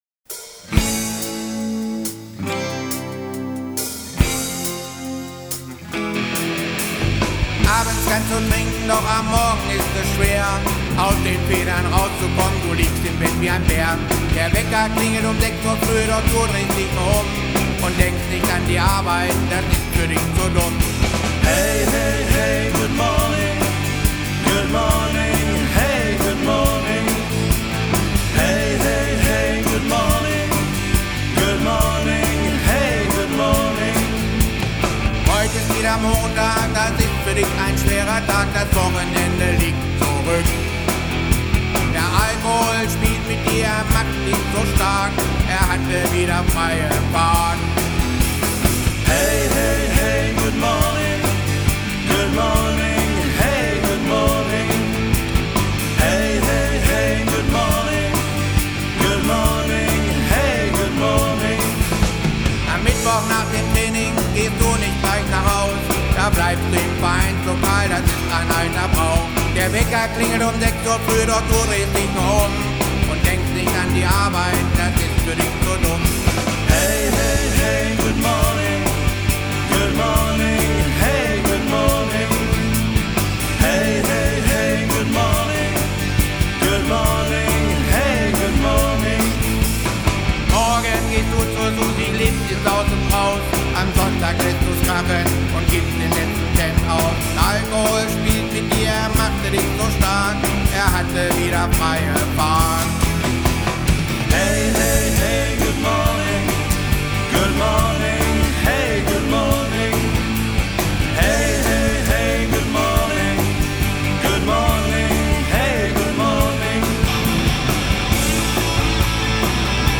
beherzte Gitarrensoli